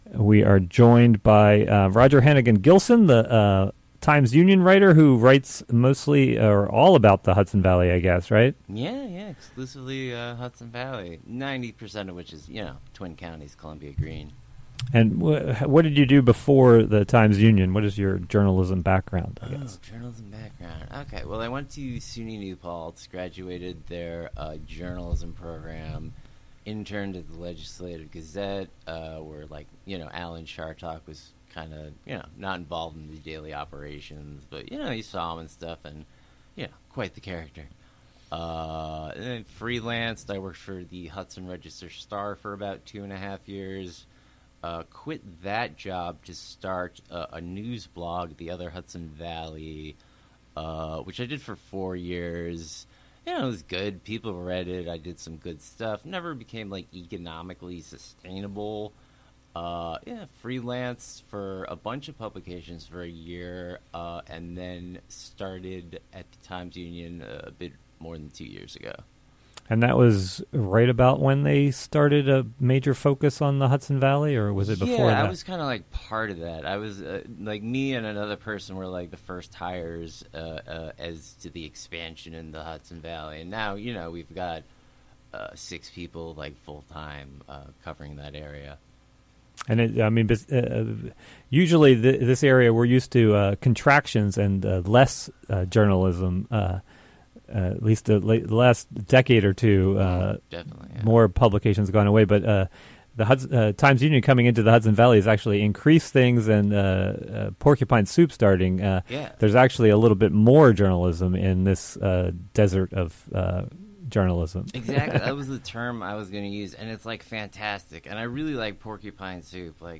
aired live on August 10, 2023 on the WGXC Morning Show
Interviewed